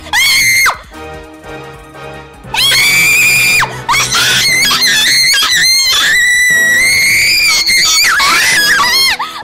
Play, download and share Haachama screaming original sound button!!!!
haachama-screaming.mp3